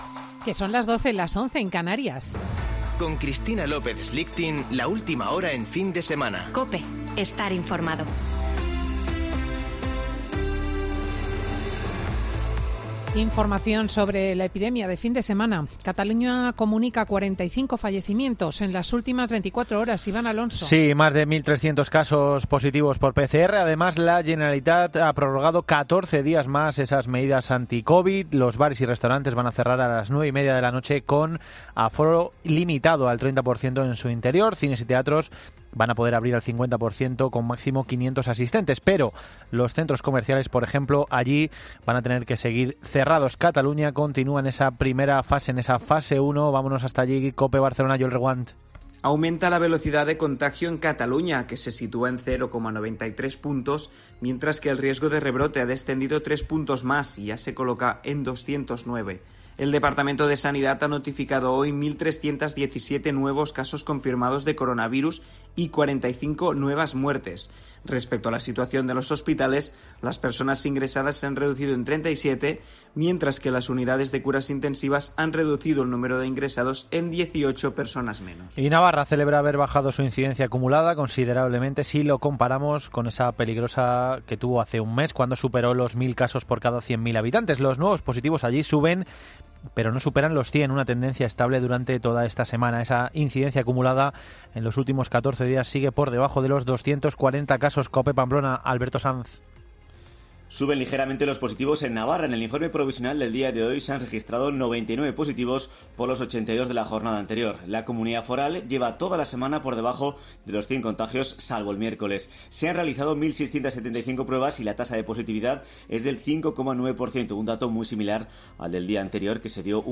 Boletín de noticias de COPE del 5 de diciembre de 2020 a las 12.00 horas